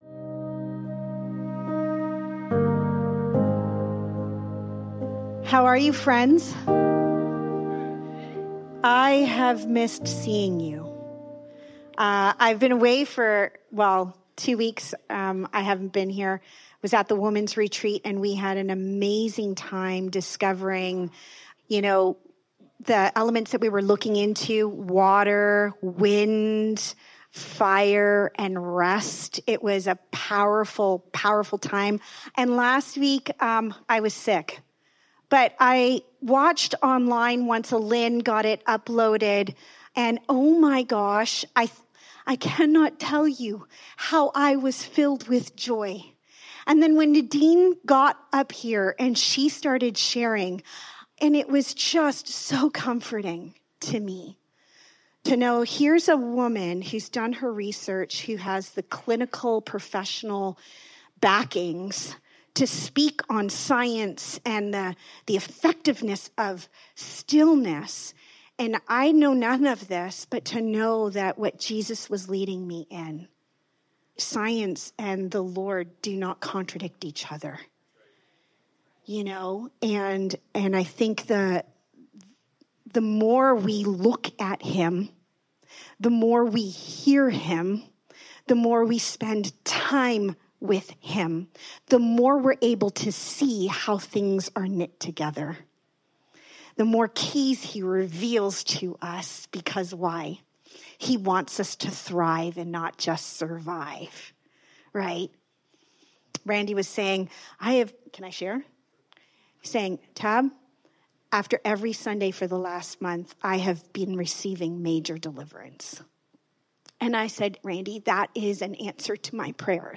Out of the Shadows Service Type: Sunday Morning This week